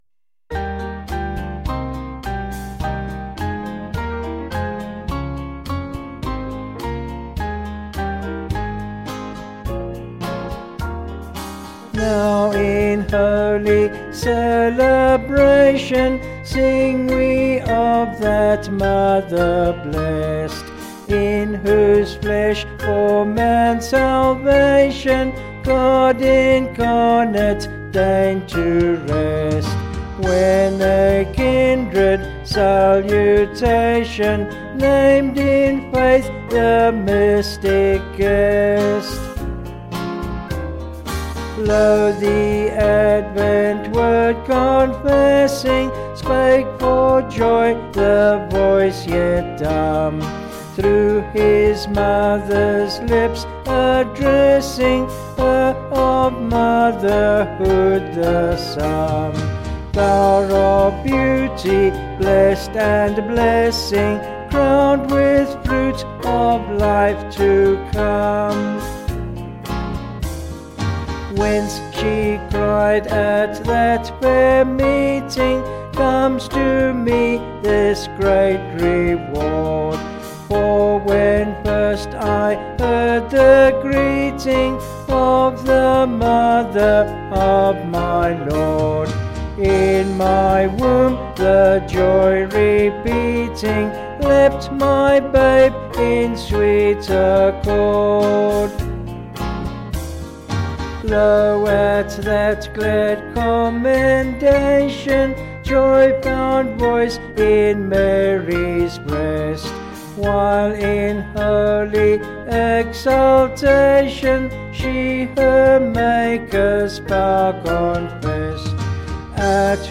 Vocals and Band   263.9kb Sung Lyrics